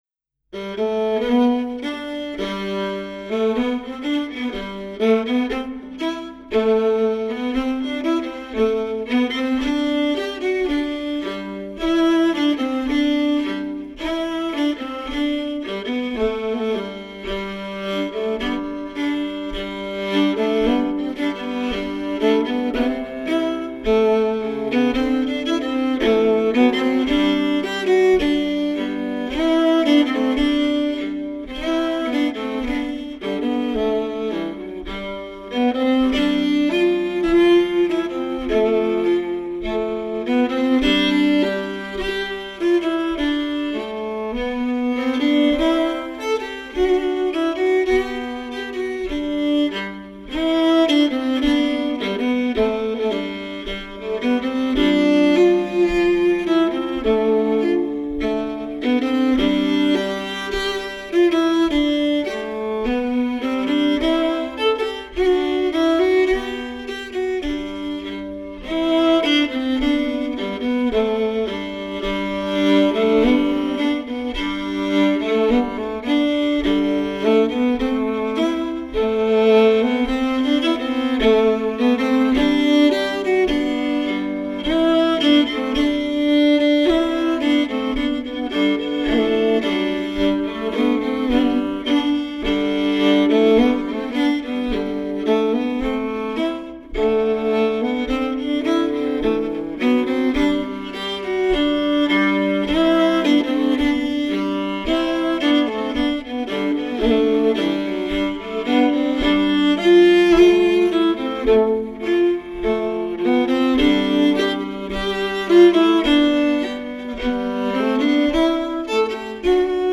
Singer, viola and fiddle player